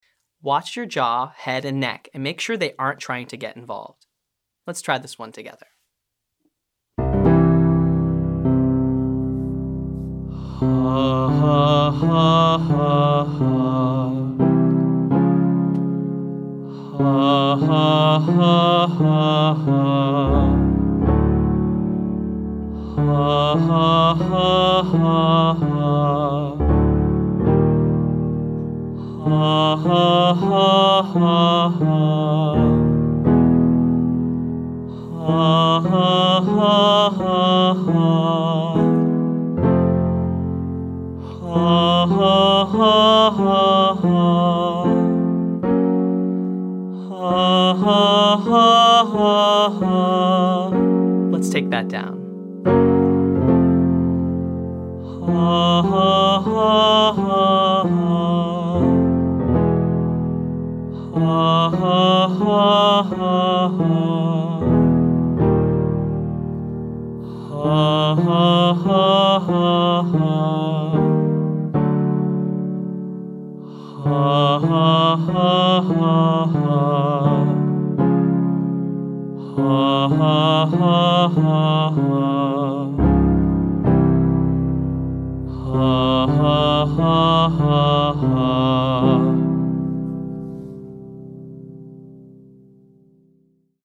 • Huh (1,2,3 )